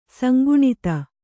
♪ sanguṇita